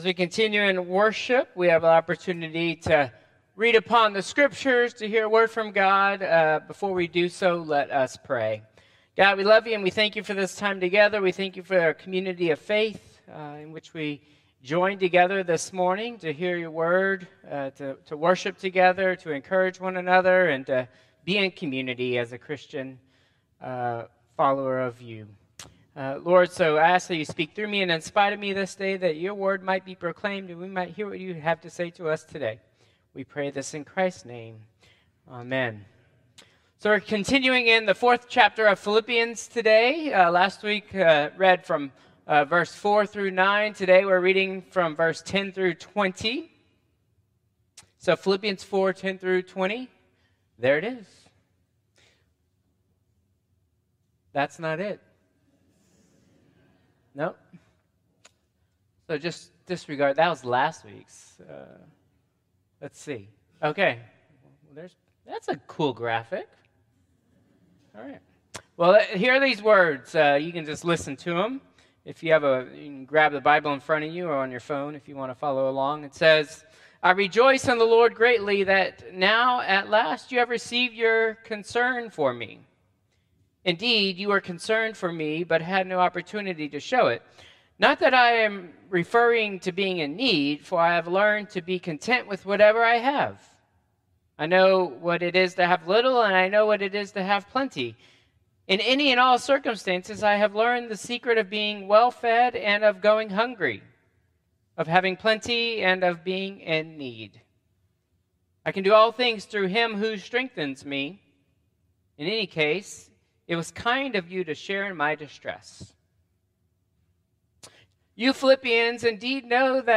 Traditional Service 11/24/2024